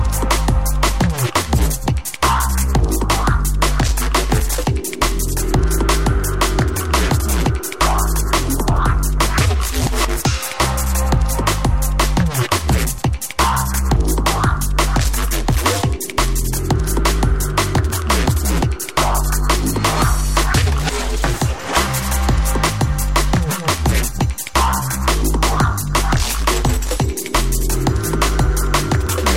TOP >Vinyl >Drum & Bass / Jungle
TOP > Jump Up / Drum Step